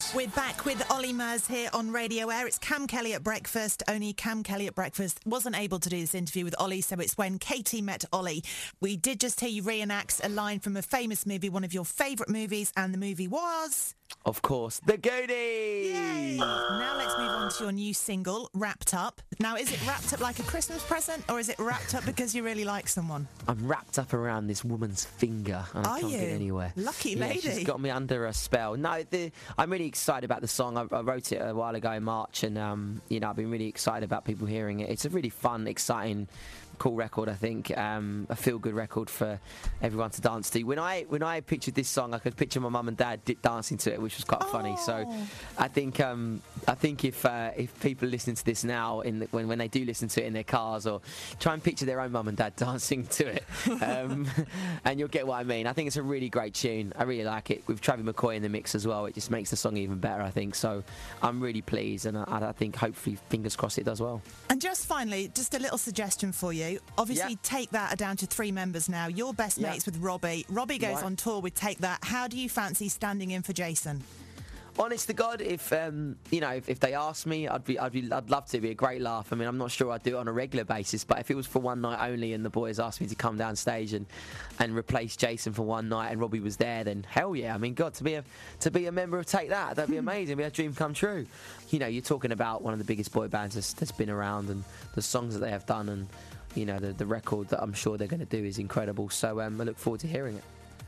chat with Olly Murs